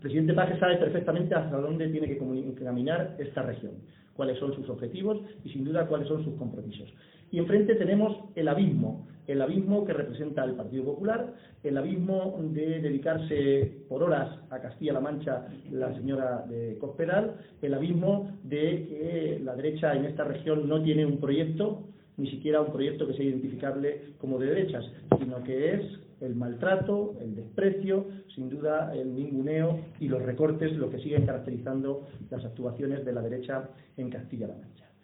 EN LA ASAMBLEA ABIERTA DEL PSOE DE SOCUÉLLAMOS
Cortes de audio de la rueda de prensa